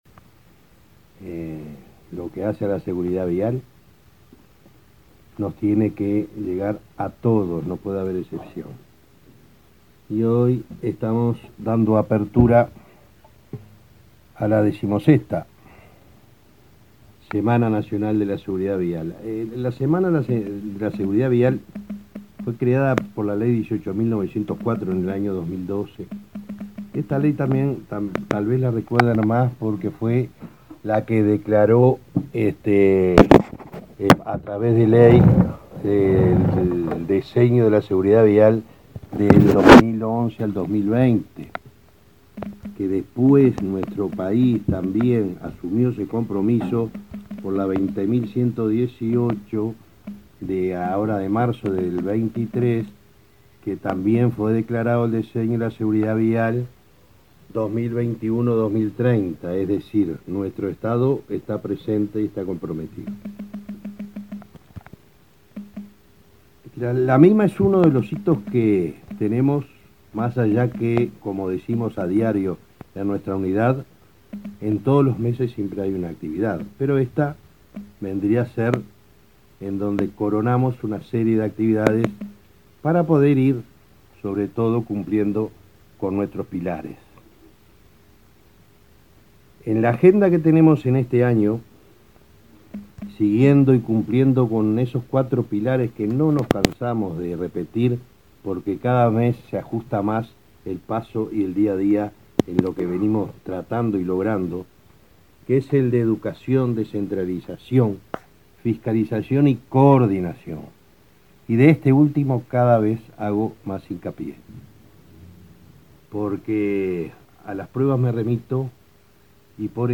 Palabras de autoridades en acto por la Semana de la Seguridad Vial
Palabras de autoridades en acto por la Semana de la Seguridad Vial 09/10/2023 Compartir Facebook X Copiar enlace WhatsApp LinkedIn El presidente de la Unidad Nacional de Seguridad Vial (Unasev), Alejandro Draper, y el ministro de Transporte, José Luis Falero, participaron este lunes 9, en la sede de la cartera ministerial, en el lanzamiento de la Semana de la Seguridad Vial.